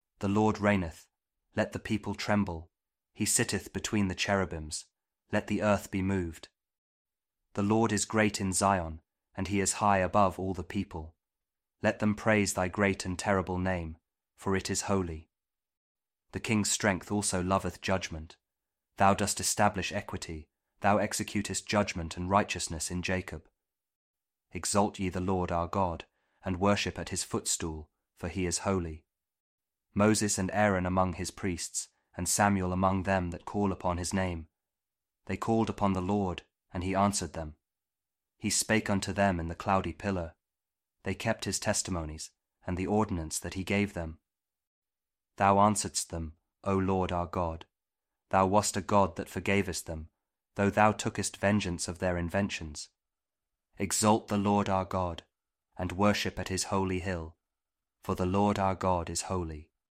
Psalm 99 | King James Audio Bible
099-psalm-king-james-audio-kjv-bible.mp3